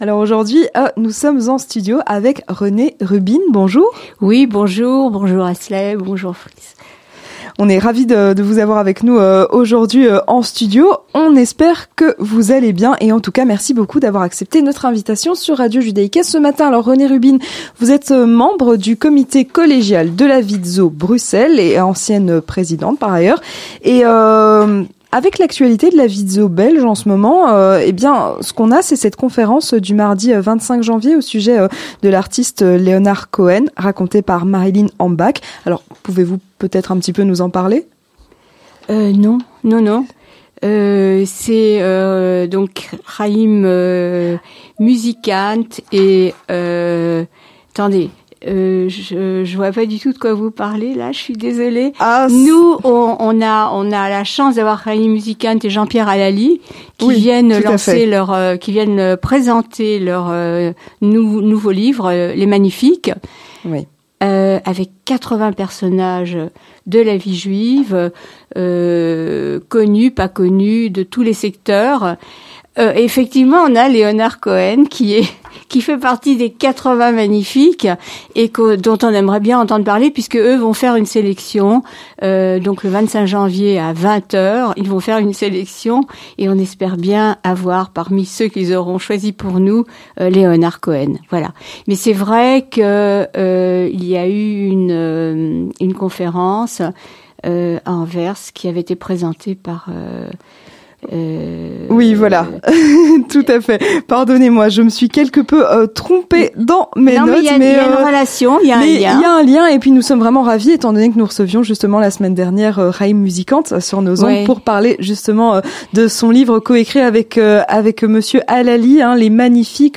L'Interview Communautaire